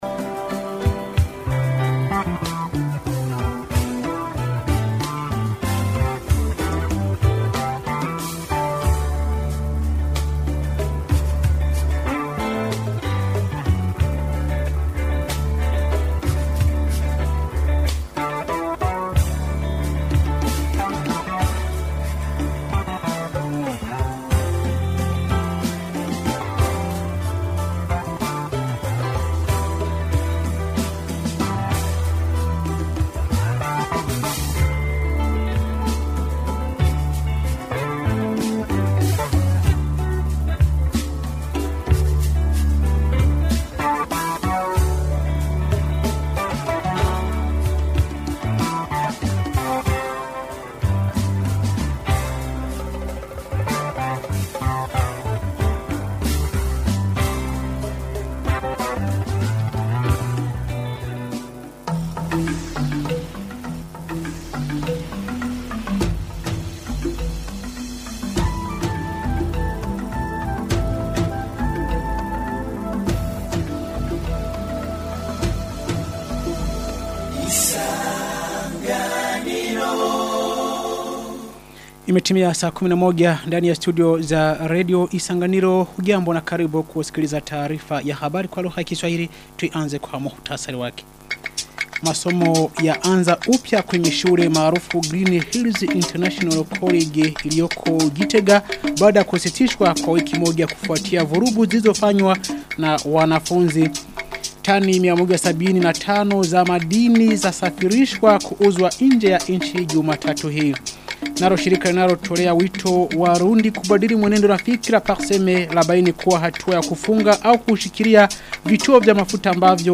Taarifa ya habari ya tarehe 27 Oktoba 2025